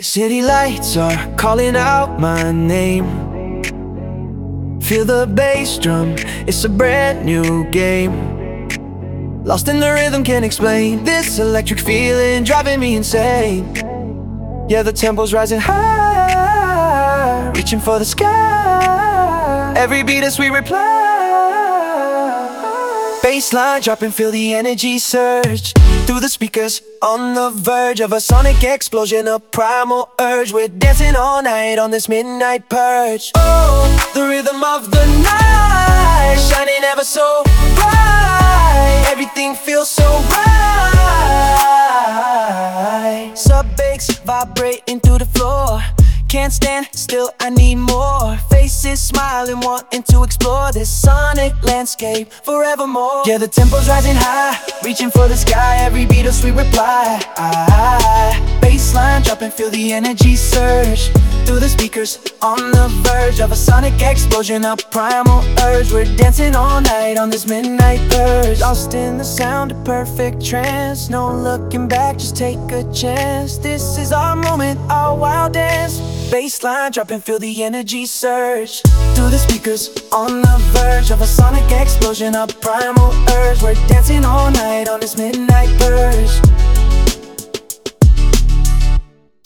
"Drum&Bass Netsky style" — generated by anonymous on the community jukebox